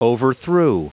Prononciation du mot overthrew en anglais (fichier audio)
overthrew.wav